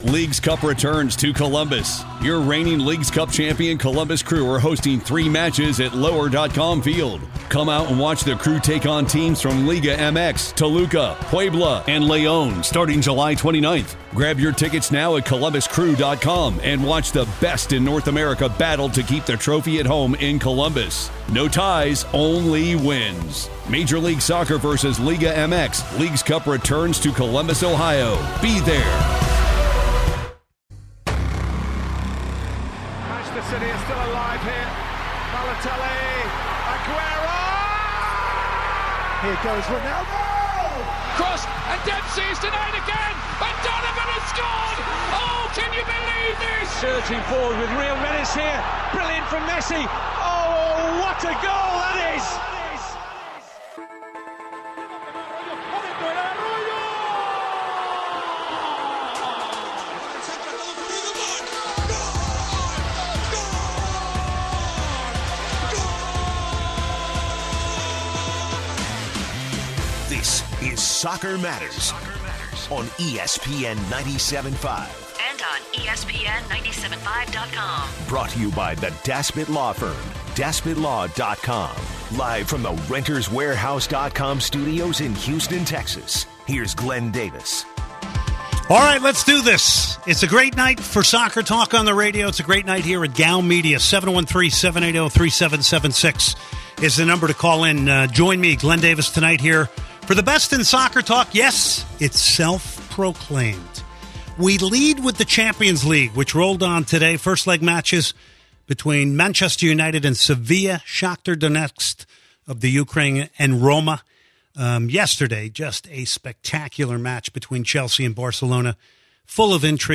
As the first half comes to a close, the listeners continue to share some fantastic stories from their first matches.